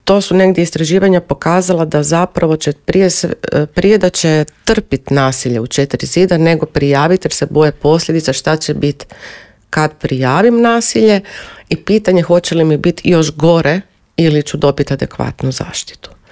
Gostujući u Intervjuu Media servisa objasnila je da je gerontologija znanstvena disciplina koja u svom fokusu ima osobe starije životne dobi.